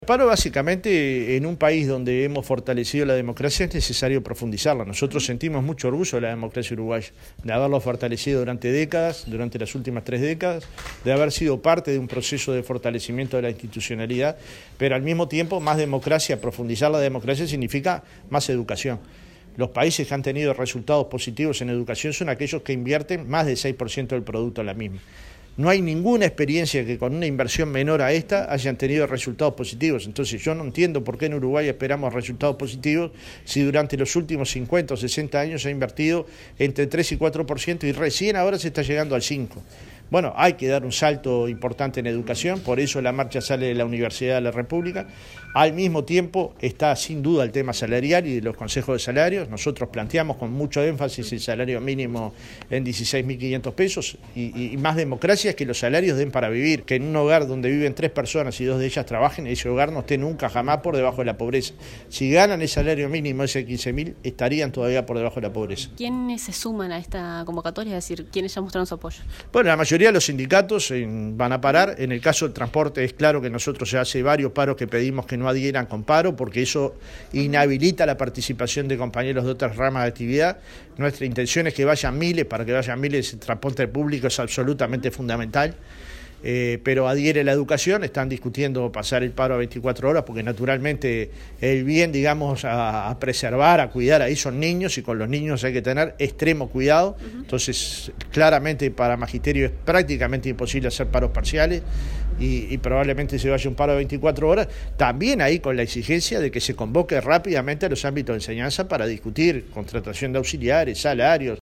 El presidente del Pit Cnt, Fernando Pereira, habló con Informativo Universal, y señaló que la plataforma incluye al salario, a la educación, la vivienda, la negociación colectiva, la rendición de cuentas y las defensas de los derechos sociales, con énfasis contra la violencia de género.